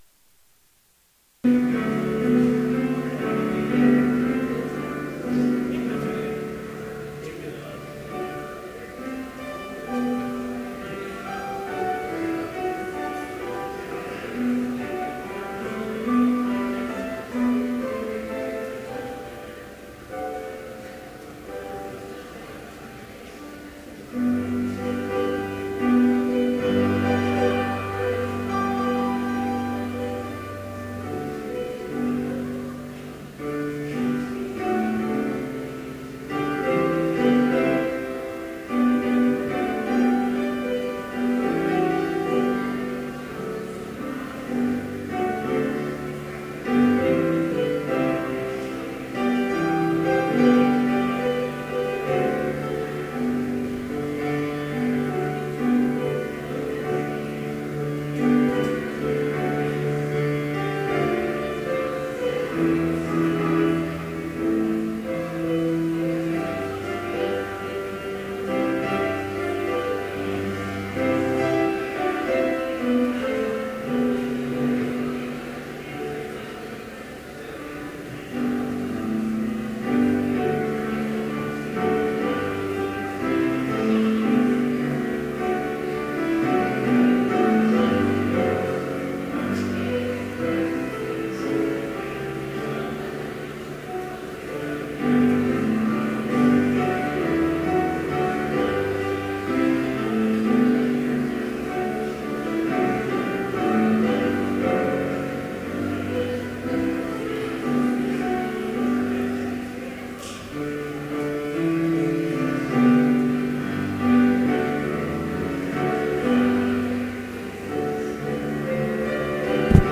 Complete service audio for Chapel - January 17, 2014